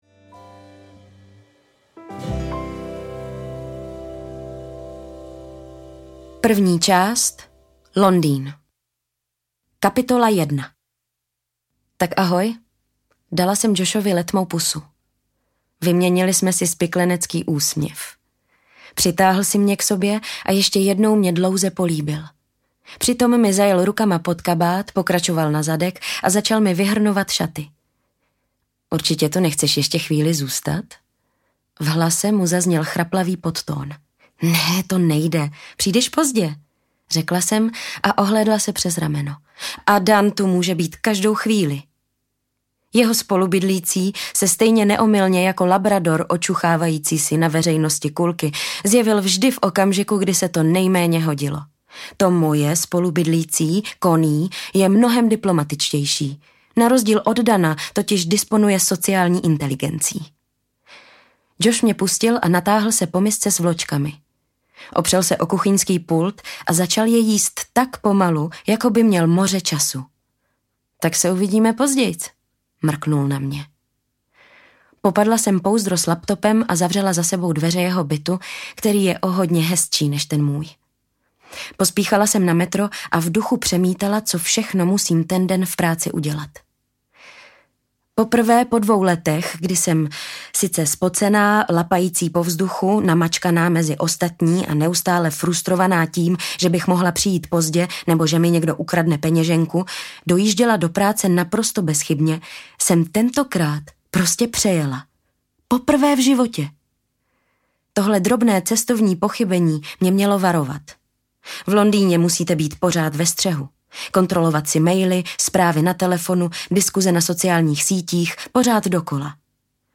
Kavárna v Kodani audiokniha
Ukázka z knihy